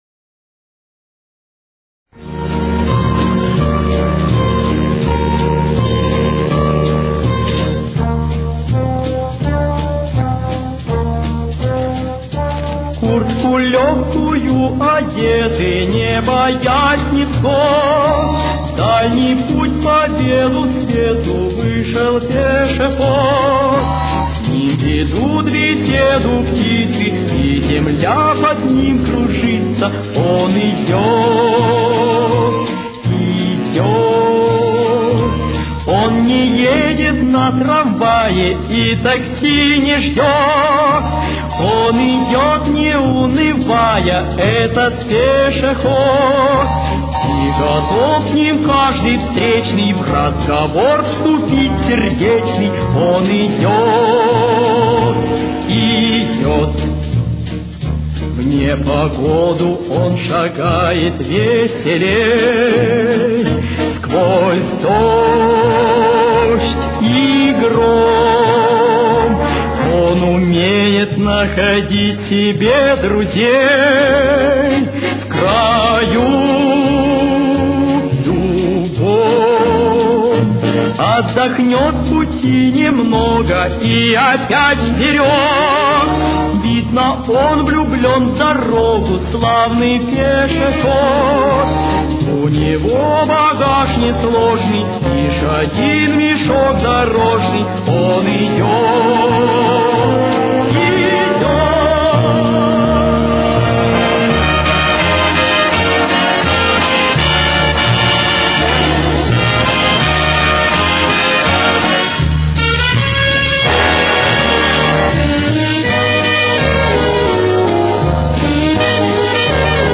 Ми-бемоль минор. Темп: 85.